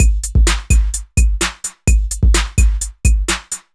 VMH1 Minimal Beats 11.wav